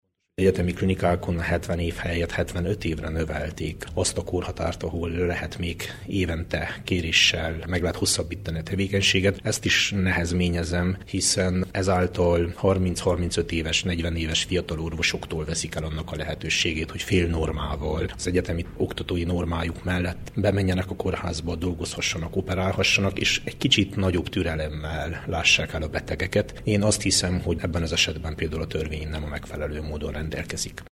A 95-ös egészségügyi törvény módosításának – a napokban történt – képviselőházi megszavazásakor az RMDSZ tartózkodott, annak ellenére, hogy a módosítások kidolgozásában részt vett a szövetség – jelentette mai marosvásárhelyi sajtótájékoztatóján Vass Levente, a Képviselőház egészségügyi szakbizottságának titkára.